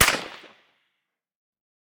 med_crack_01.ogg